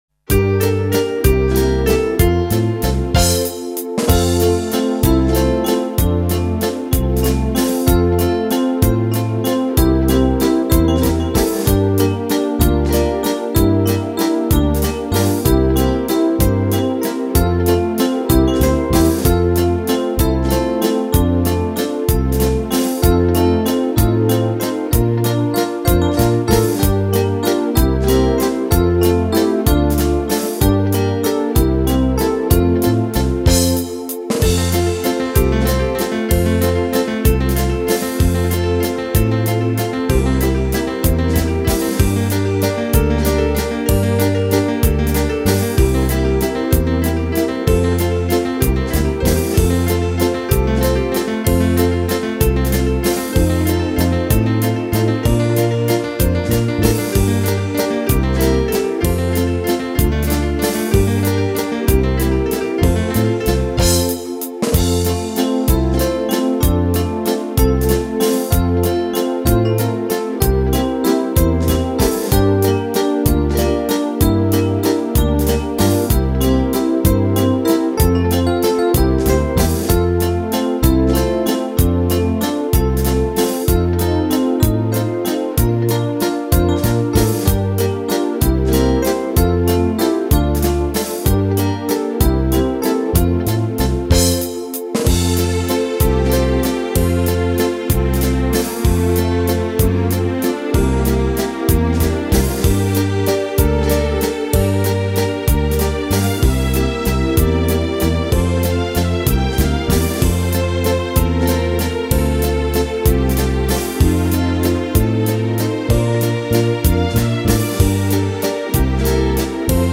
Valse